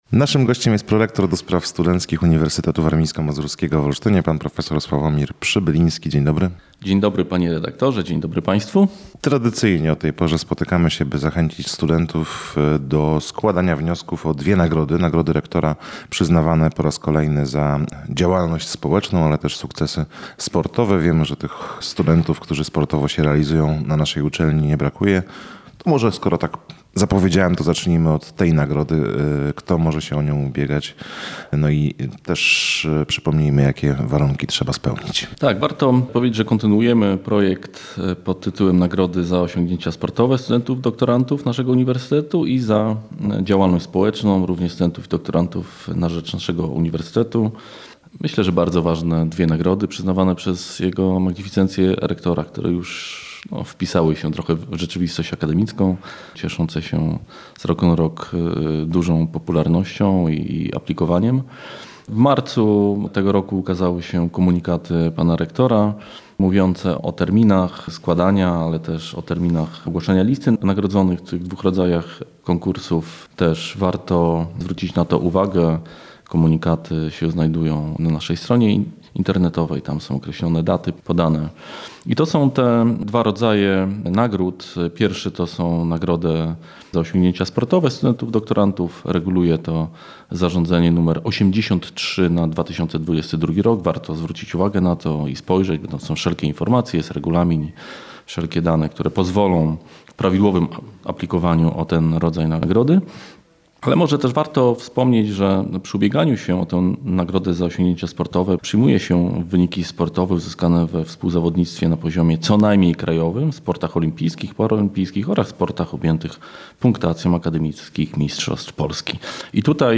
Szczegóły w rozmowie